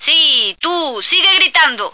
F1squeel.ogg